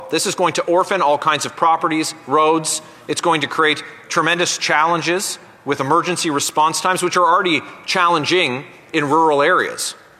The Leeds-Grenville-Thousand Islands-Rideau Lakes MP spoke in the House of Commons for nearly 15 minutes, highlighting his objections.